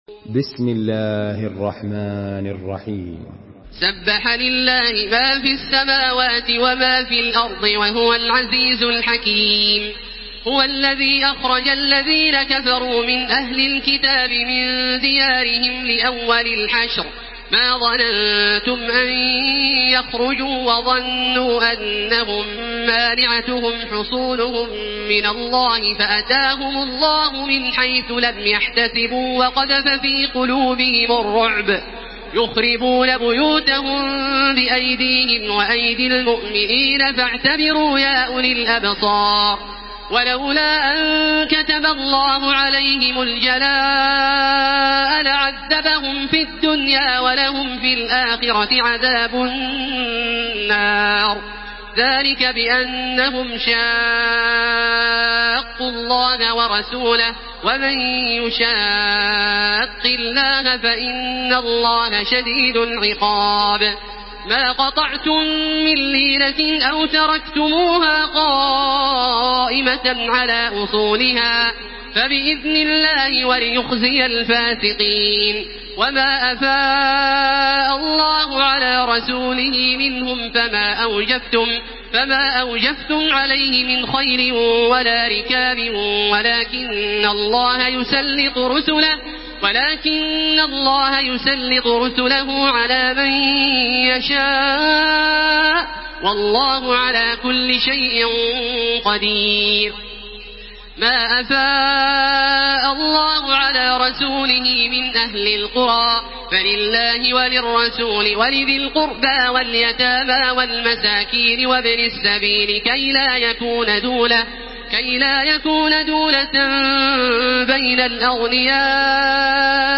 Surah Al-Hashr MP3 by Makkah Taraweeh 1433 in Hafs An Asim narration.
Murattal